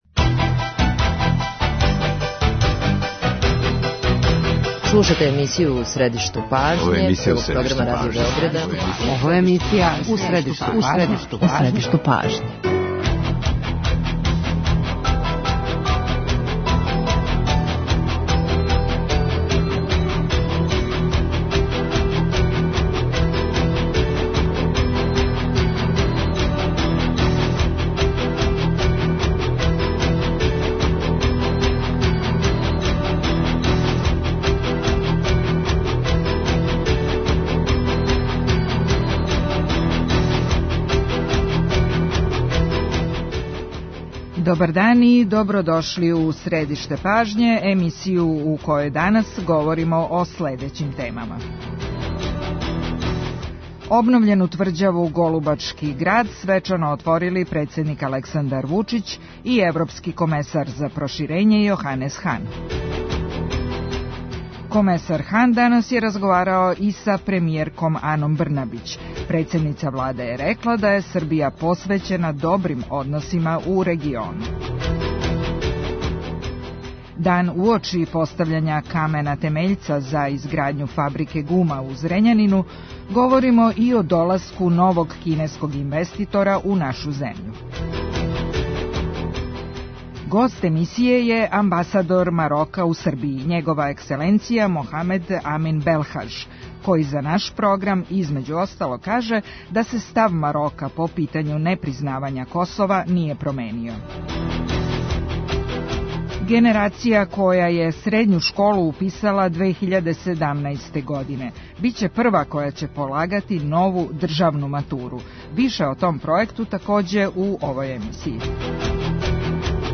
Позиција Марока по питању непризнавања независности Косова остаје непромењена, каже гост емисије Мохамед Амин Белхаж, амбасадор те земље у Србији.